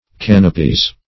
Canopy \Can"o*py\ (k[a^]n"[-o]*p[y^]), n.; pl. Canopies
(-p[i^]z). [OE. canapie, F. canap['e] sofa, OF. conop['e]e,